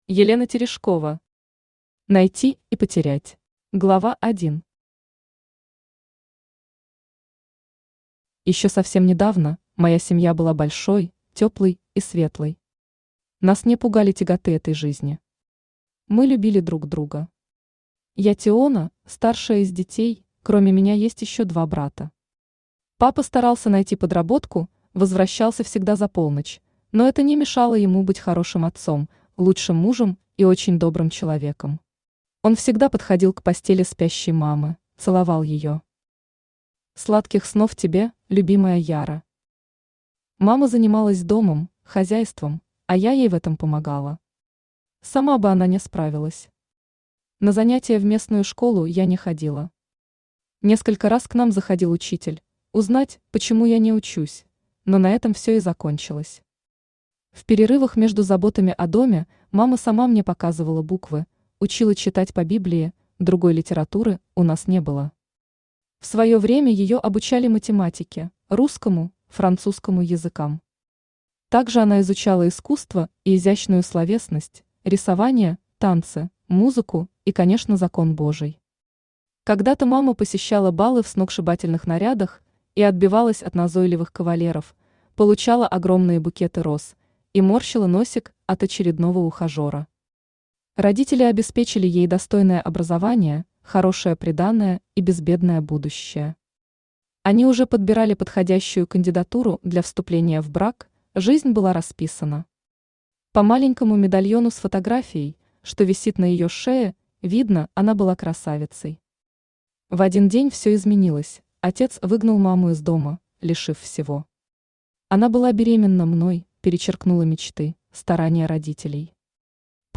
Автор Елена Терешкова Читает аудиокнигу Авточтец ЛитРес.